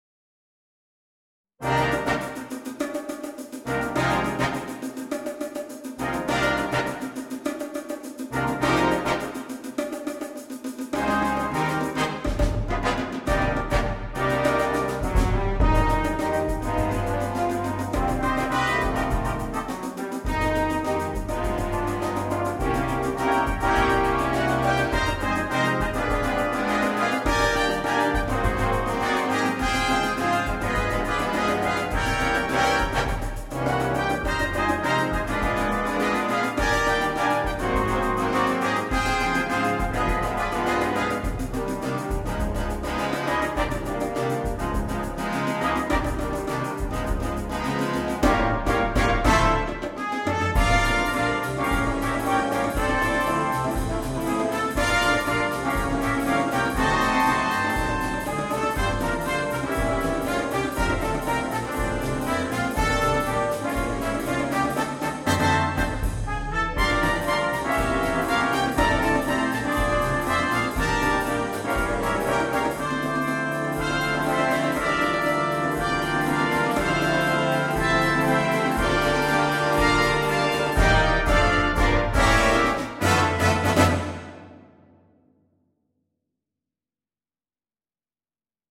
для духового оркестра.